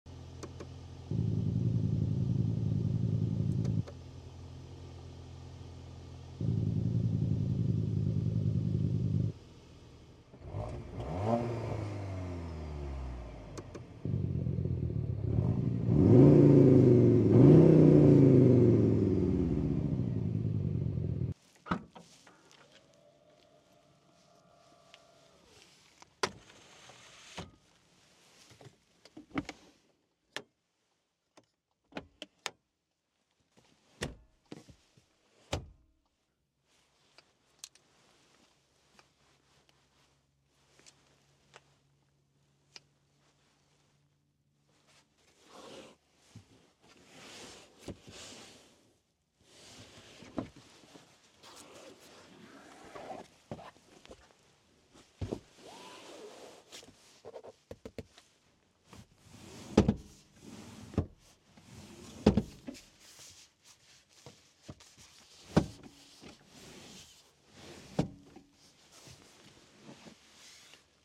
External sound generator near the exhaust of the all-new Skoda Kodiaq vRS. You can also hear the difference in the interior, although it doesn’t come from the internal speakers.